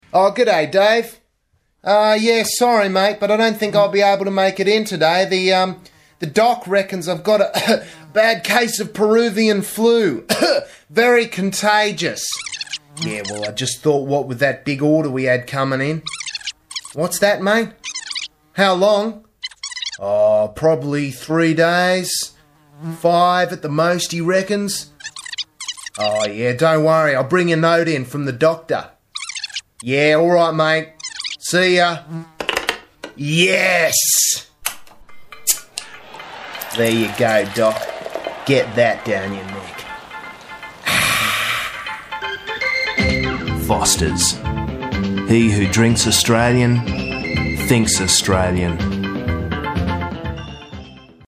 Commercial, Comedy, Young, Chatty
Australian